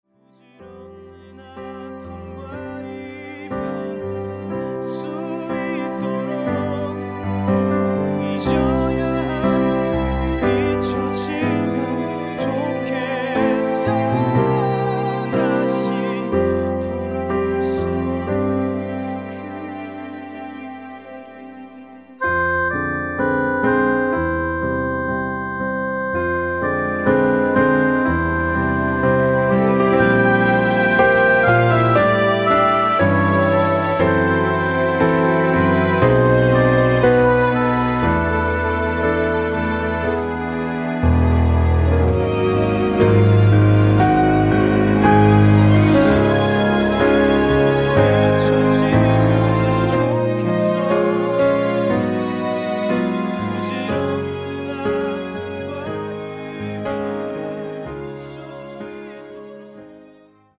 음정 원키 3:10
장르 가요 구분 Voice Cut